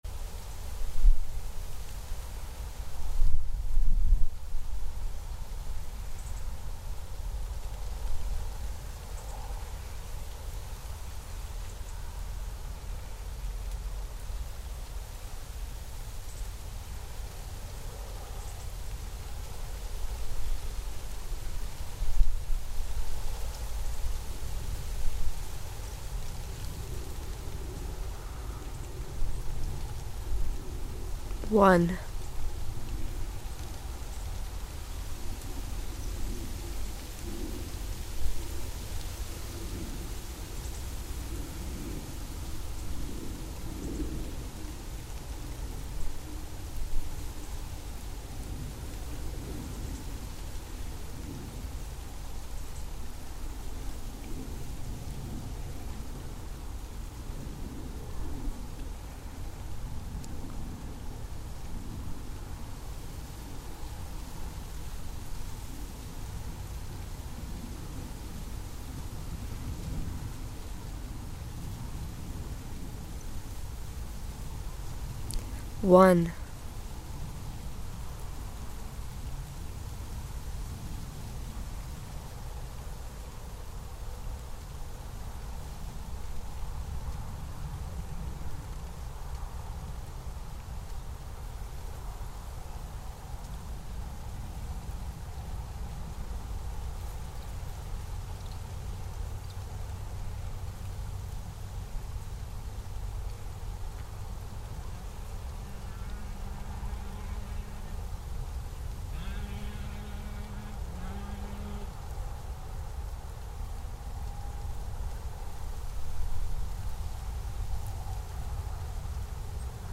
Genre:  Field Recordings / Drone Ambient
This is a really cool little compilation with a focus on the use of field recordings that have strong natural rhythm.